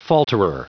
Prononciation du mot falterer en anglais (fichier audio)
Prononciation du mot : falterer